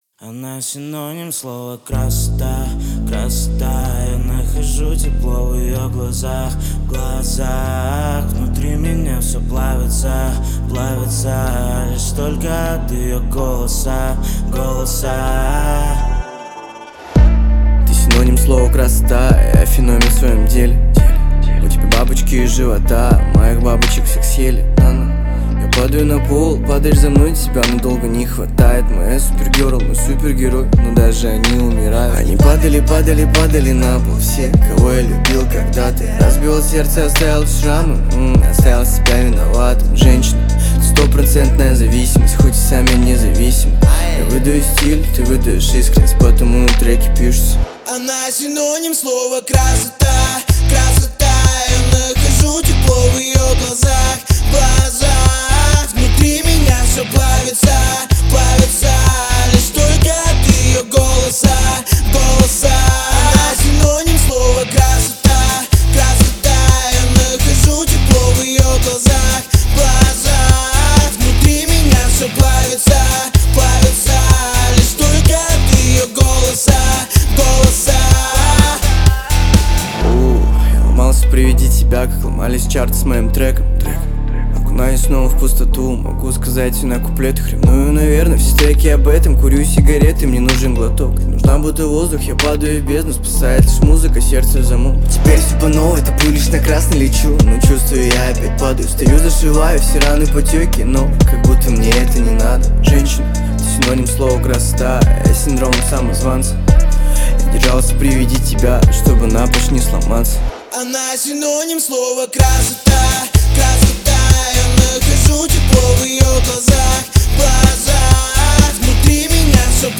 • Жанр: Русские